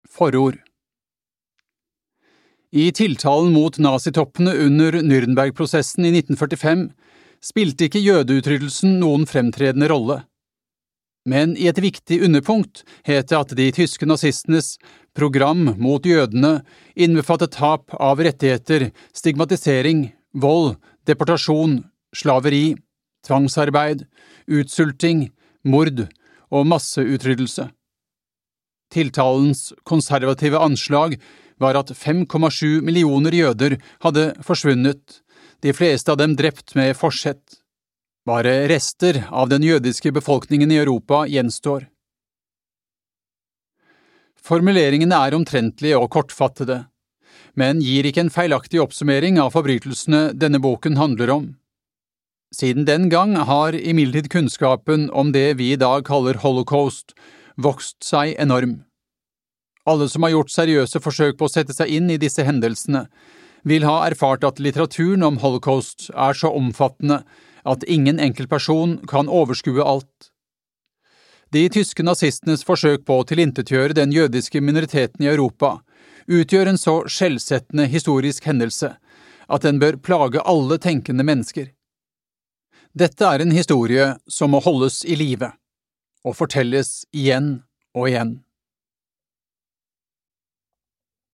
Holocaust - en historie fortalt av ofrene (lydbok) av Frode Helland